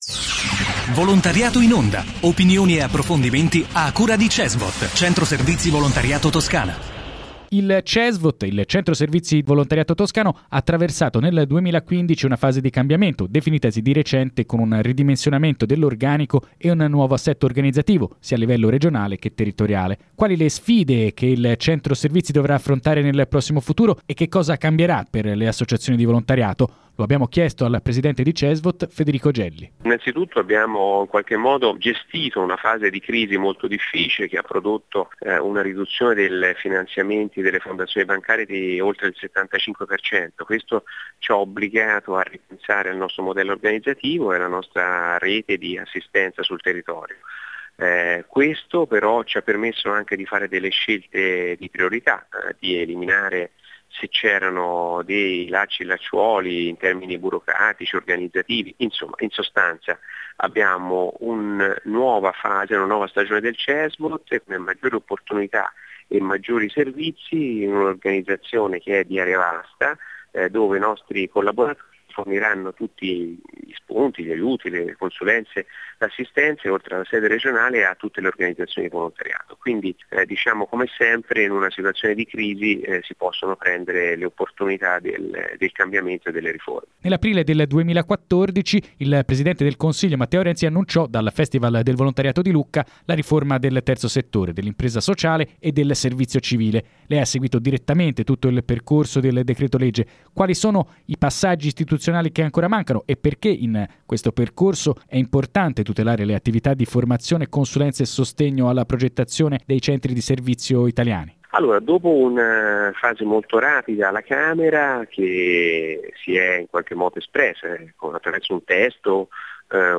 Anche questa settimana sono tante le inziative del volontariato che sentirete sulle principali radio toscane. Tre, infatti, le rubriche promosse da Cesvot, in collaborazione con Controradio, Contatto Radio, Radio Toscana, Novaradio: Associazioni in radio, Volontariato in onda e Agenda Cesvot.